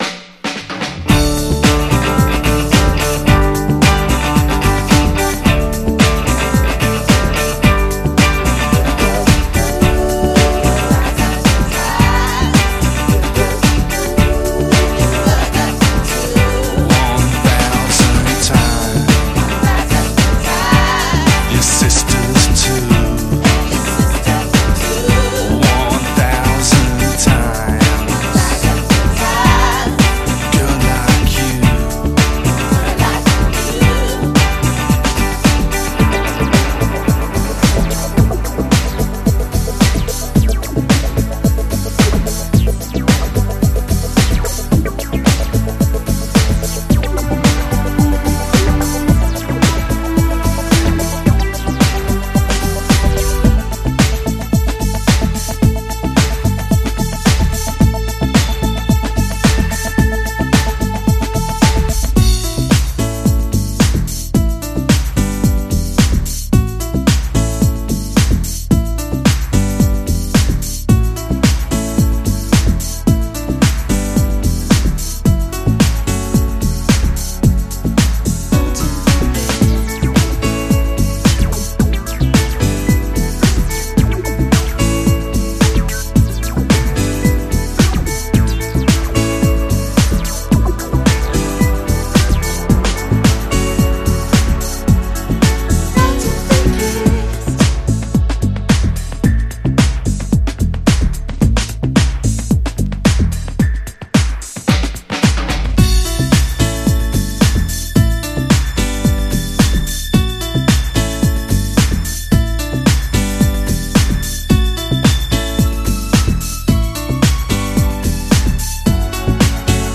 マッドチェスター/インディーダンス好きも必聴！